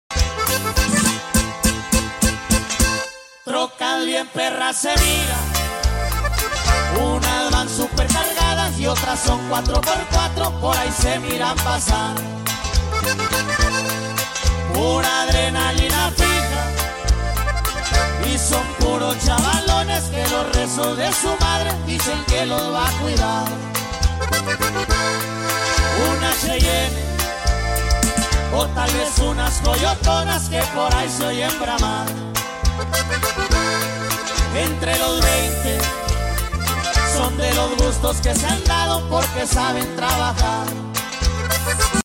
‼2018 Chevy Silverado Single Cab sound effects free download